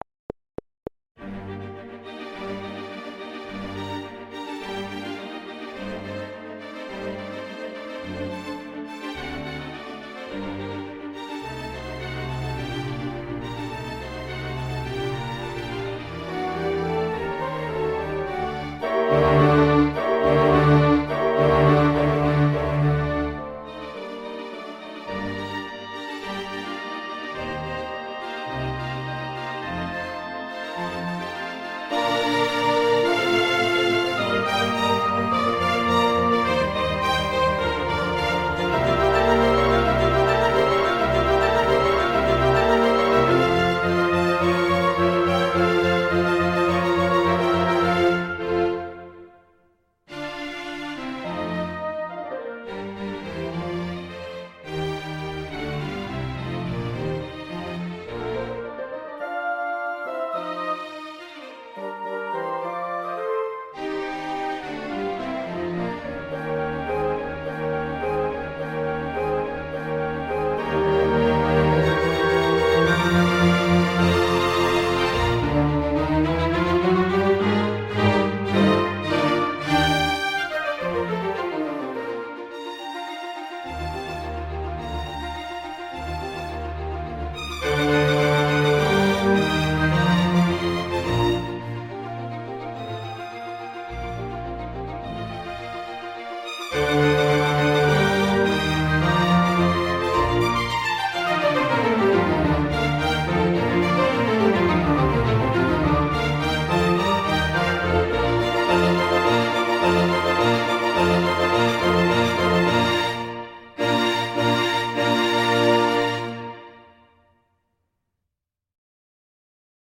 El acompañamiento es la versión con orquesta.
El acompañamiento: